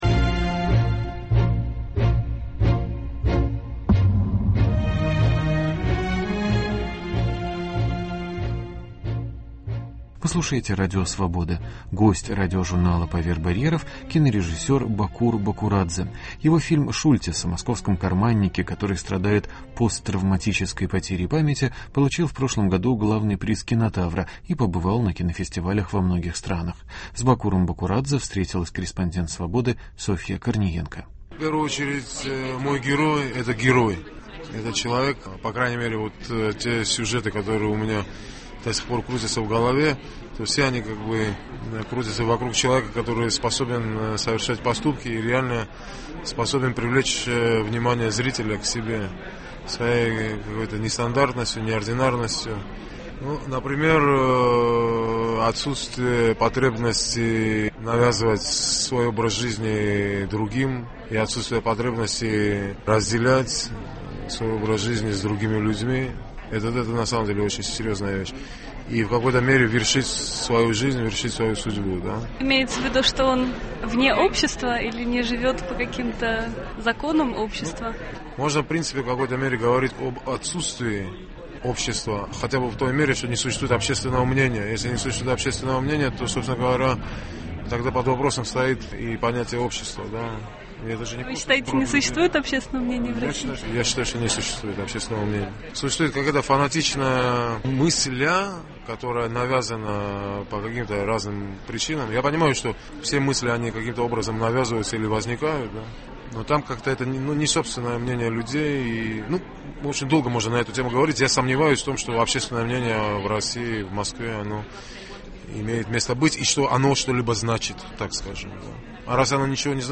Интервью с кинорежиссером Бакуром Бакурадзе